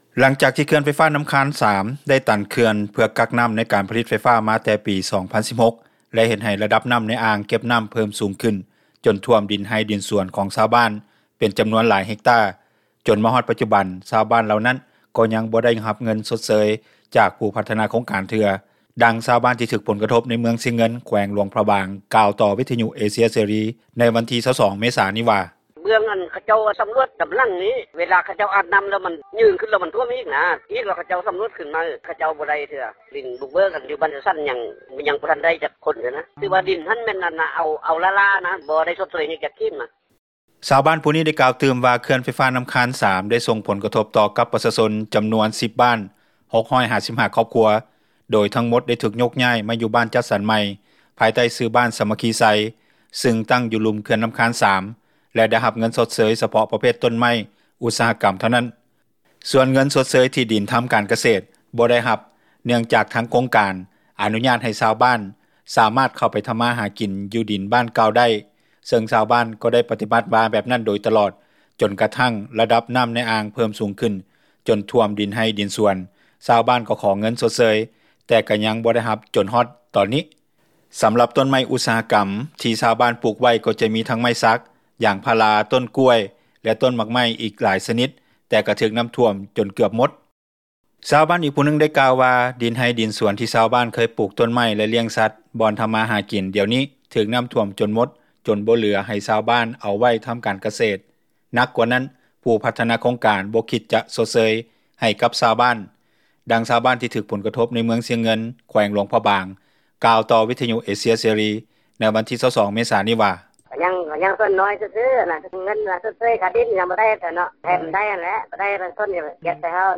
ດັ່ງຊາວບ້ານທີ່ຖືກຜົລກະທົບ ໃນເມືອງຊຽງເງິນ ແຂວງຫລວງພຣະບາງ ກ່າວຕໍ່ວິທຍຸເອເຊັຽເສຣີໃນວັນທີ 22 ເມສານີ້ວ່າ:
ດັ່ງເຈົ້າໜ້າທີ່ ເມືອງຊຽງເງິນ ແຂວງຫລວງພຣະບາງ ທ່ານນຶ່ງກ່າວຕໍ່ວິທຍຸເອເຊັຽເສຣີ ໃນວັນທີ 22 ເມສານີ້ວ່າ: